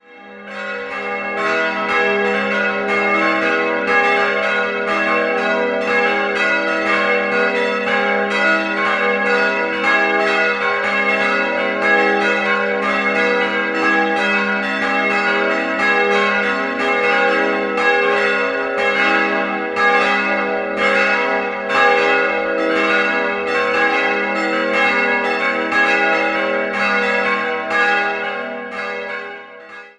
Idealquartett gis'-h'-cis''-e'' Die Glocken wurden 1960 von Friedrich Wilhelm Schilling in Heidelberg gegossen.